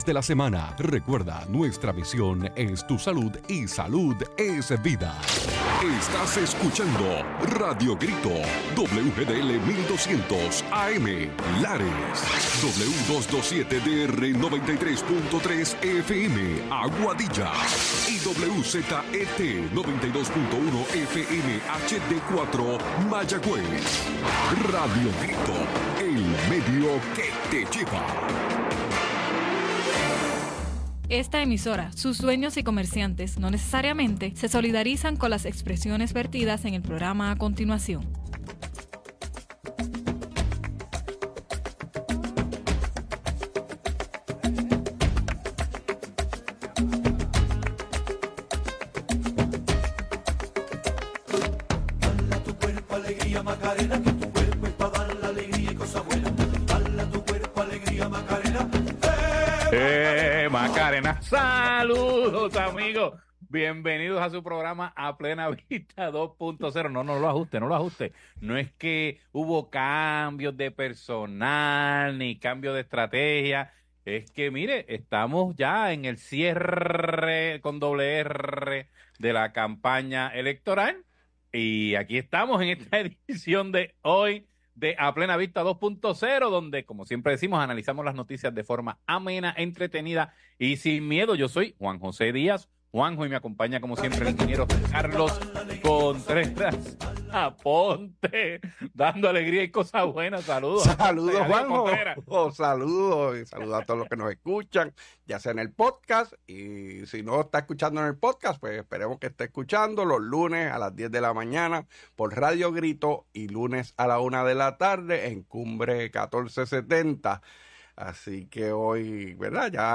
Hoy en a plena vista dialogan sobre los cierres de campaña de ambos partidos políticos.